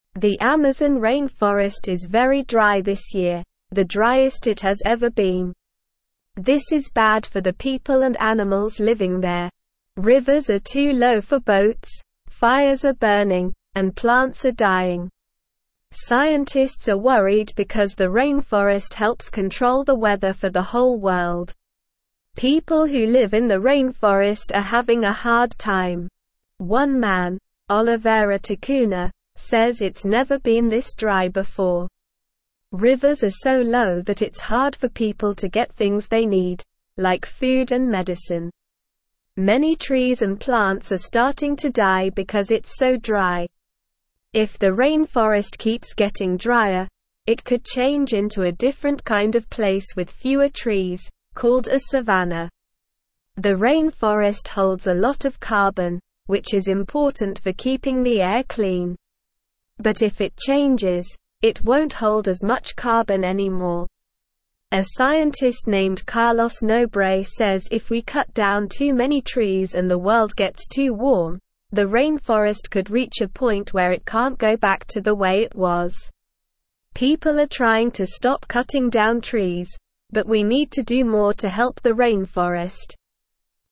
Slow
English-Newsroom-Lower-Intermediate-SLOW-Reading-Amazon-Forest-in-Trouble-Drought-Fires-and-Concerns.mp3